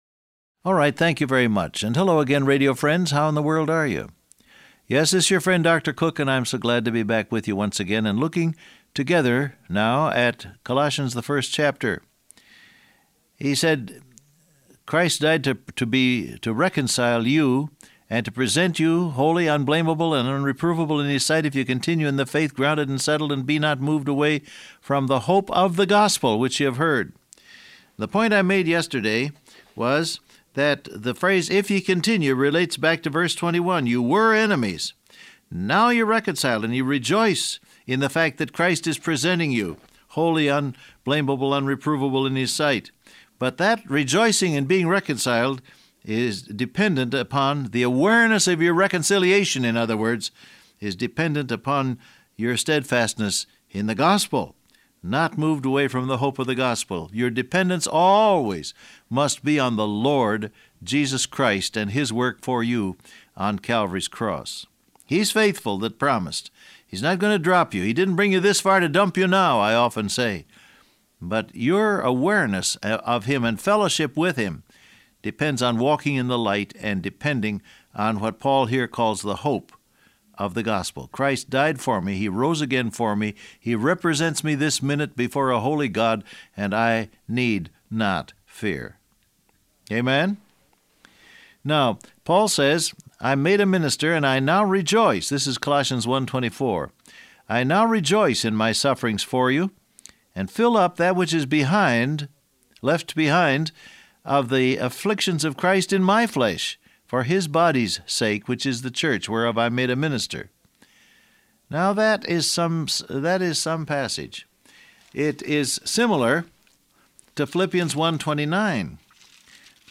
Download Audio Print Broadcast #1869 Scripture: Colossians 1:21-22 , Philippians 1:29 Transcript Facebook Twitter WhatsApp Alright, thank you very much, and hello again radio friends, how in the world are you?